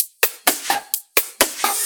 Index of /VEE/VEE2 Loops 128BPM
VEE2 Electro Loop 055.wav